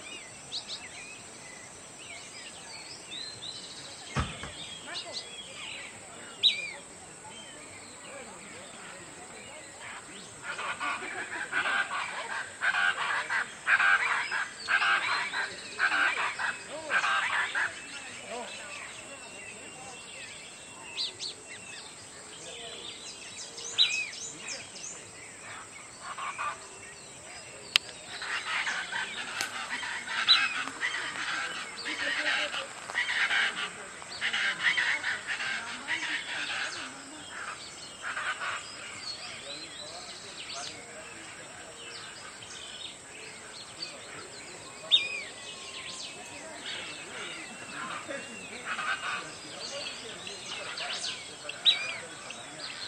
Fiofío Pico Corto (Elaenia parvirostris)
Nombre en inglés: Small-billed Elaenia
Localidad o área protegida: Trancas
Condición: Silvestre
Certeza: Fotografiada, Vocalización Grabada
fiofio-pico-corto-mp3.mp3